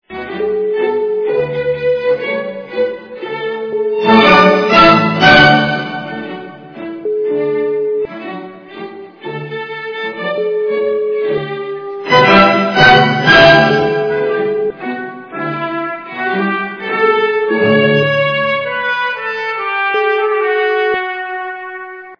классика